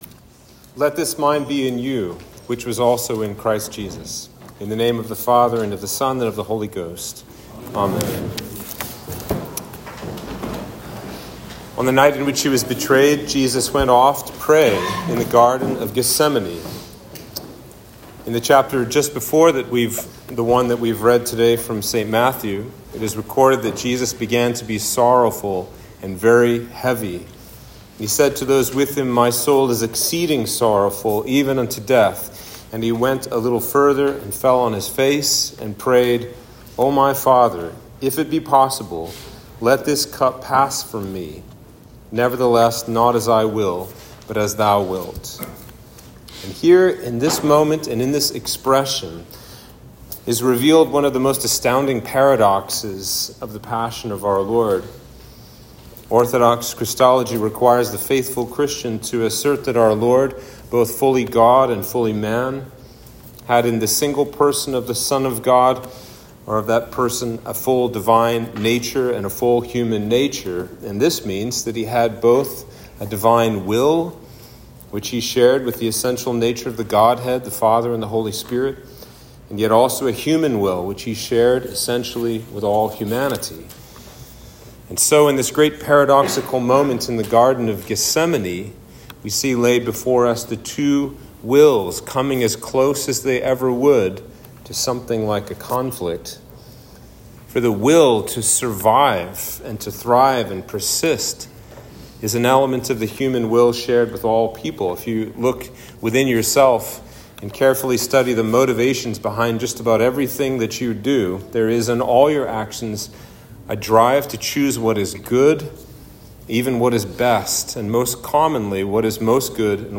Sermon for Palm Sunday